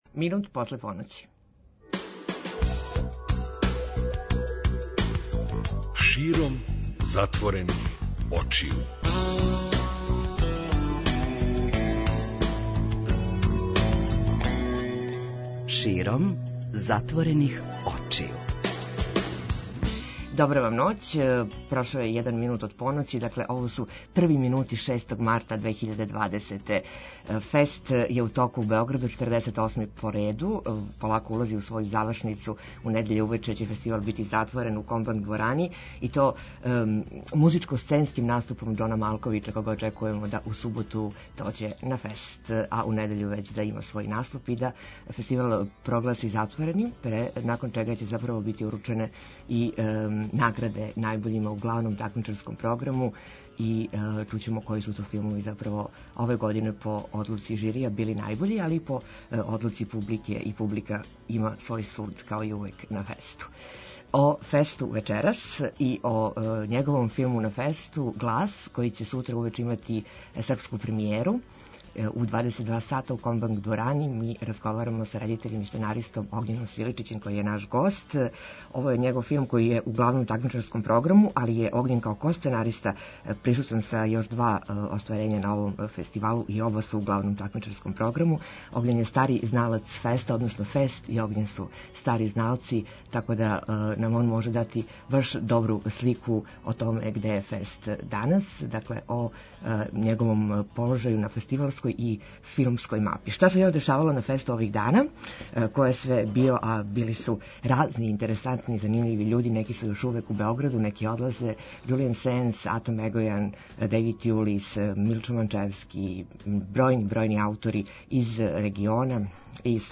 Ноћни разговори са гостима 48. ФЕСТ-а